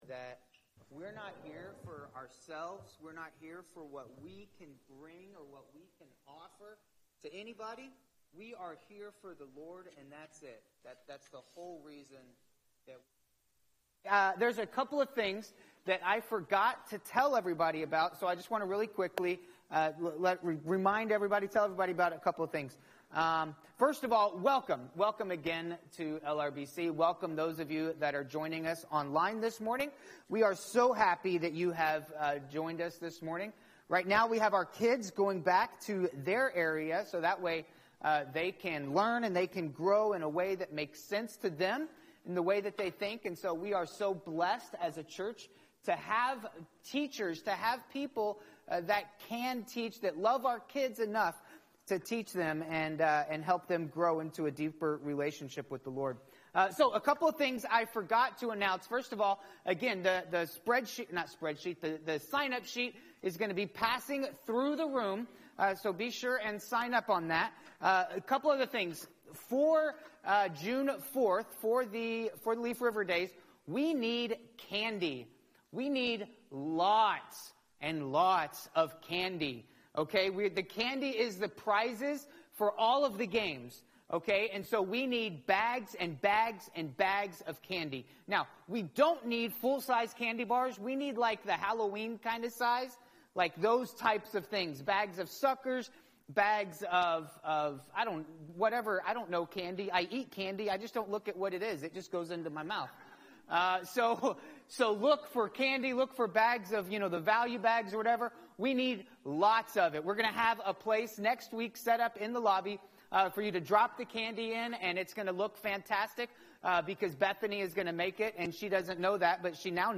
Messages | Leaf River Baptist Church